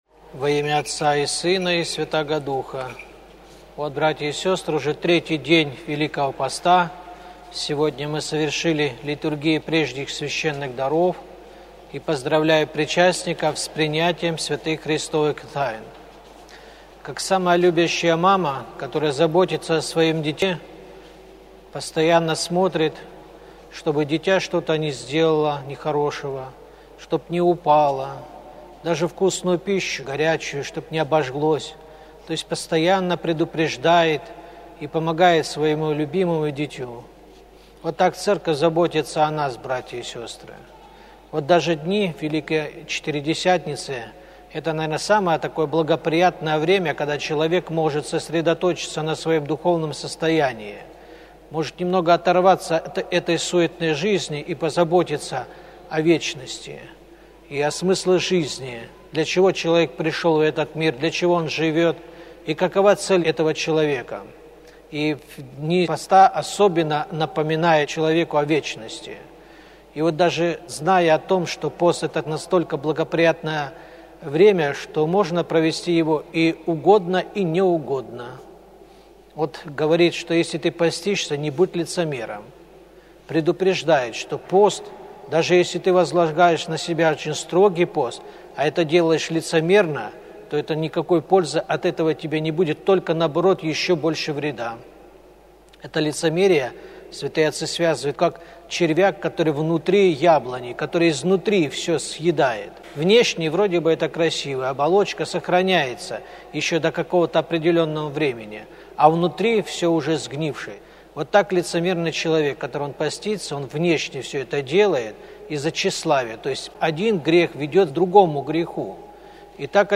Проповедь Аудио запись
Проповедь епископа Балтийского Серафима после богослужения